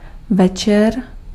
Ääntäminen
IPA : /ˈnaɪt/